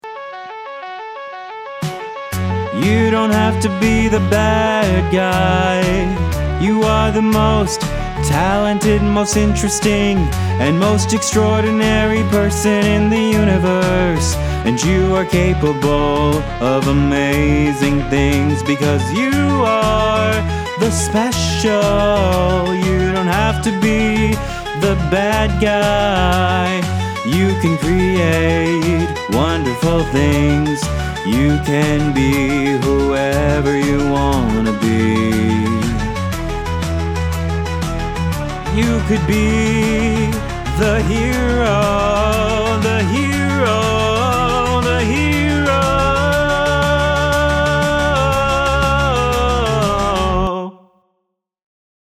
Demo
Teenager, Young Adult, Adult
COMMERCIAL FILTER conversational